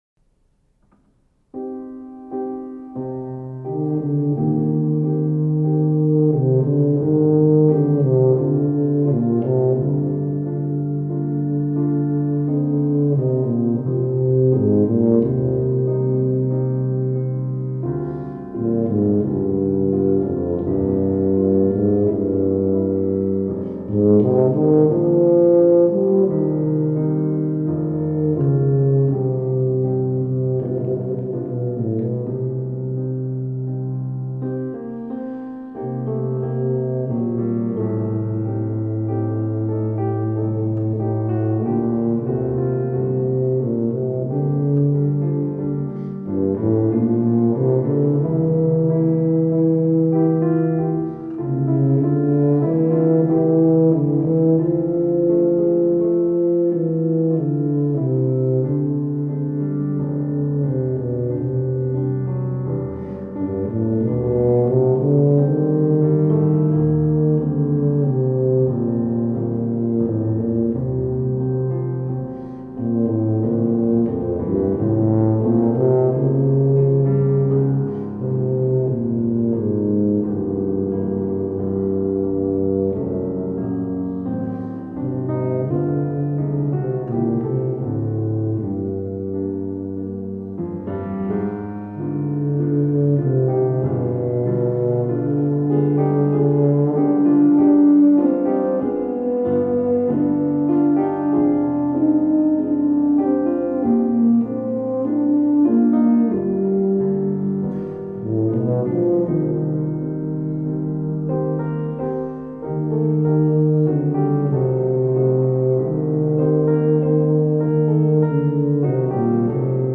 for tuba and piano